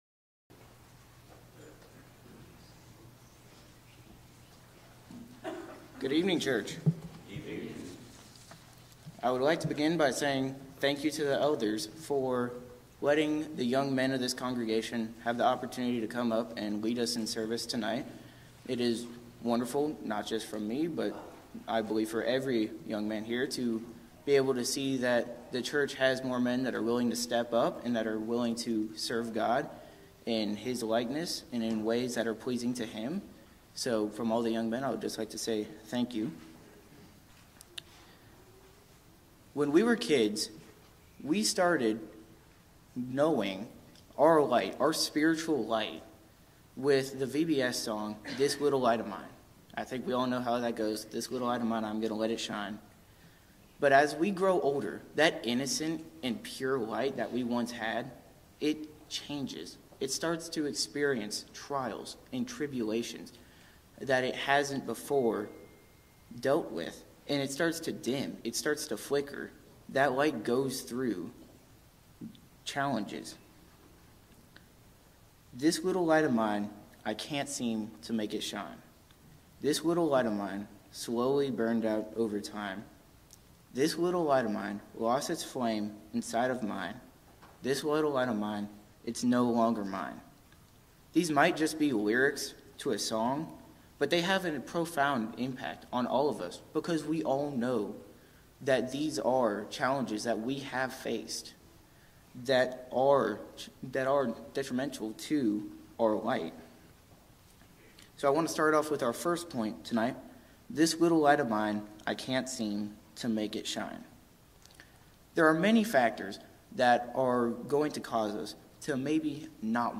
Young Men’s Service